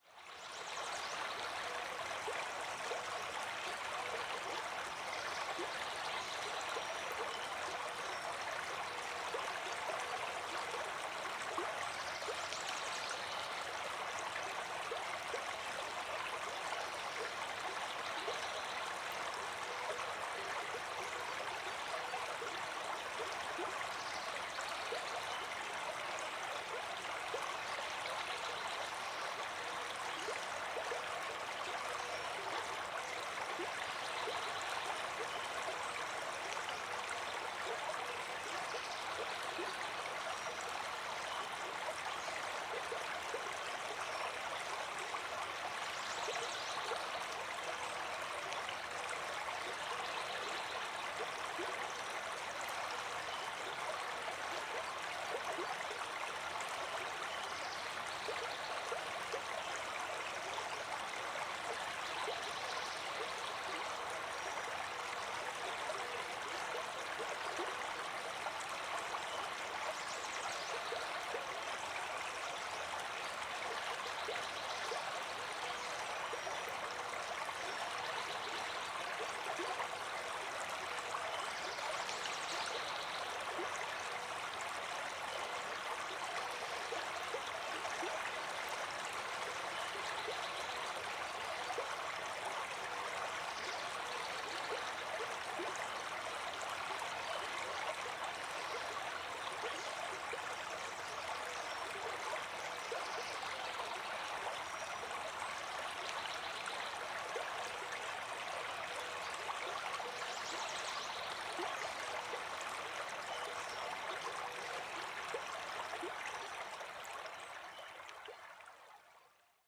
Tiếng Chim hót và tiếng Suối chảy trong rừng
Thể loại: Tiếng thiên nhiên
Description: Tiếng Chim hót và tiếng Suối chảy trong rừng là âm thanh thiên nhiên đa dạng, có tiếng róc rách chảy của suối trong rừng, tiếng chim ca hót véo von, âm thanh rừng núi hoang sợ gợi ra không gian rộng mở, yên bình, tiếng nước chảy êm đềm, hoang sơ mở ra không gian rừng rậm hùng vĩ, chưa có bóng dáng khai hoang của con người có thể dùng để làm nhạc nền kể chuyện...
Tieng-chim-hot-va-tieng-suoi-chay-trong-rung-www_tiengdong_com.mp3